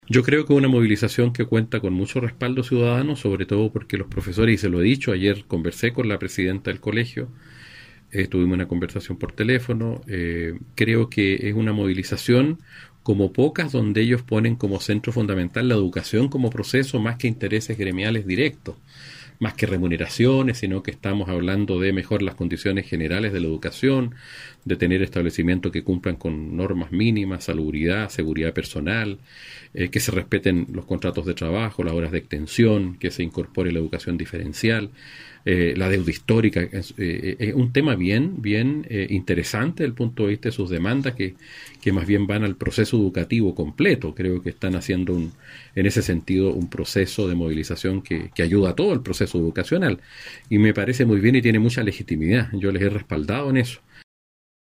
En este contexto, el Diputado Jaime Mulet, manifestó su apoyo a la movilización y las demandas que persiguen los docentes: